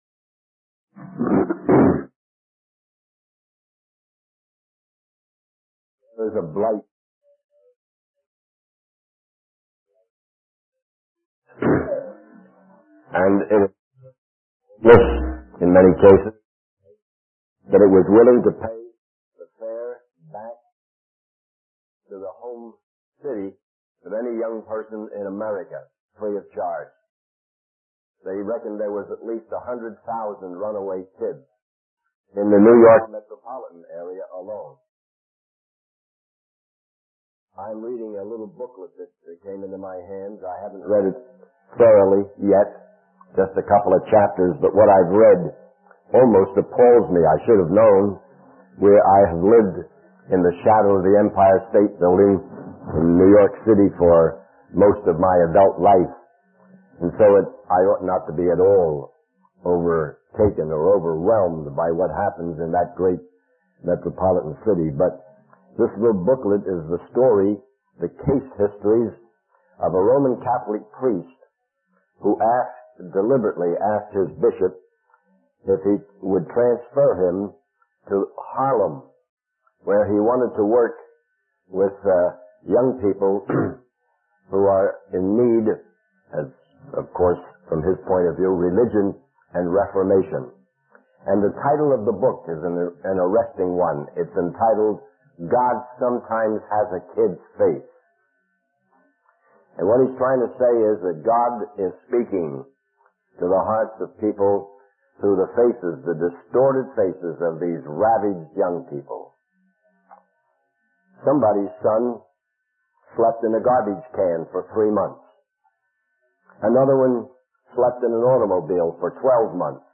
In this sermon, the preacher discusses a book called 'God Sometimes Has a Kid's Face' written by a Roman Catholic priest who requested to work with young people in need in Harlem.